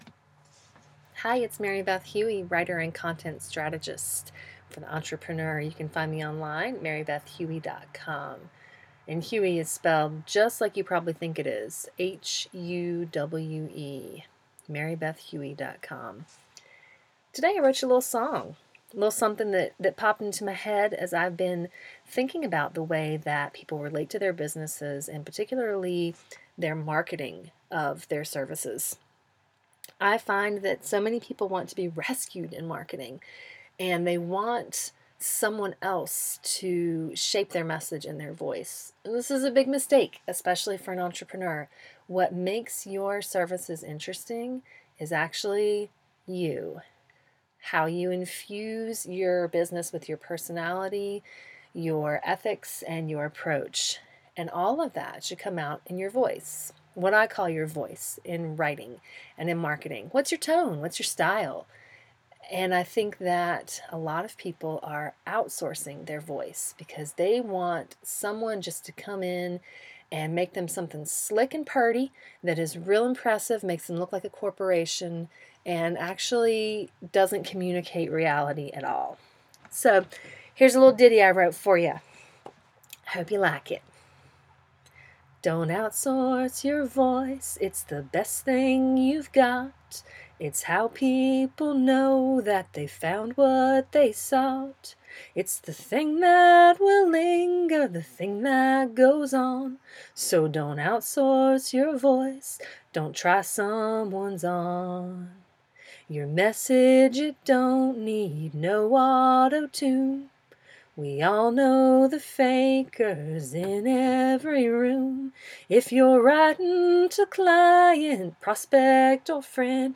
So I wrote a little ditty about it… see below.